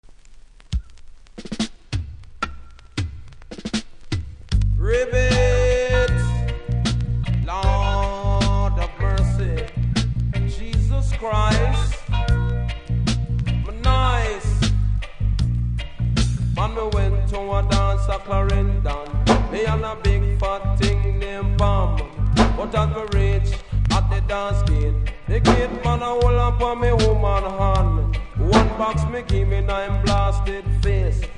後半キズによりノイズ拾います。